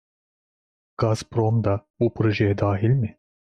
Pronounced as (IPA)
/da.hil/